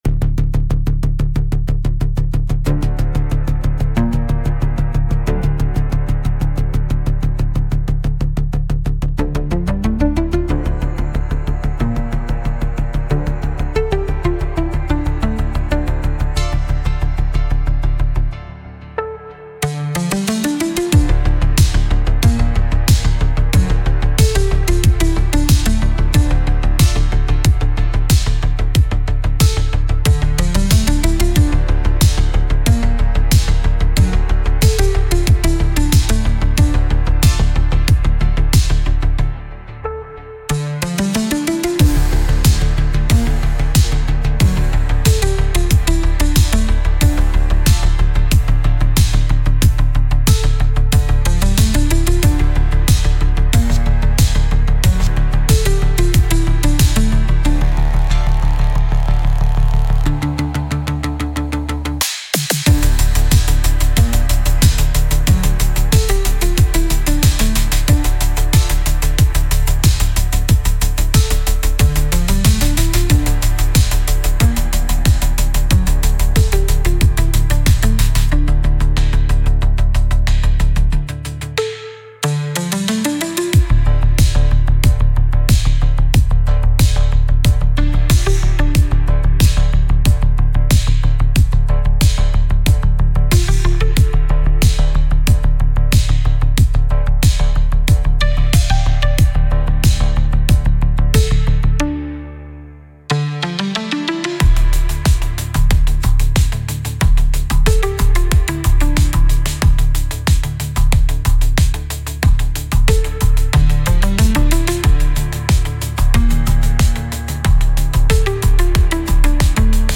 Instrumental - Vein Pulse - 4 mins